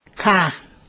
khà